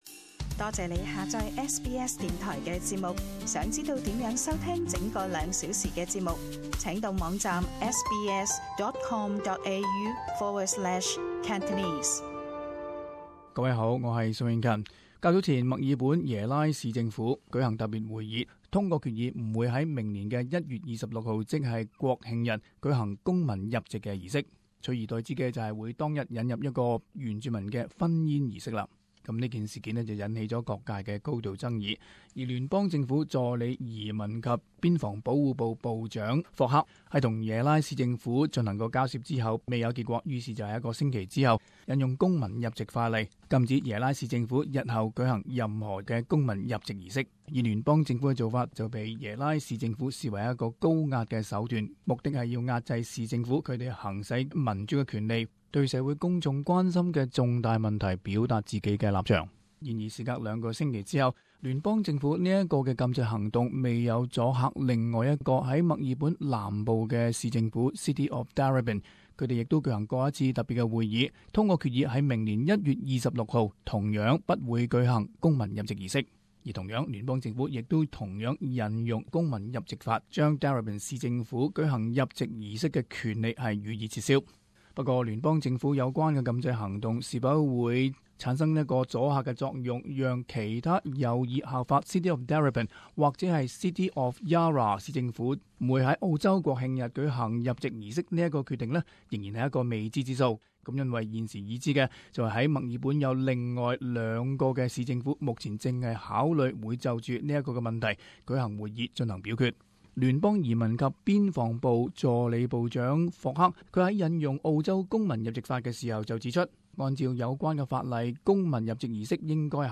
【時事報導】有市政府被撤銷舉辦公民入籍儀式的權利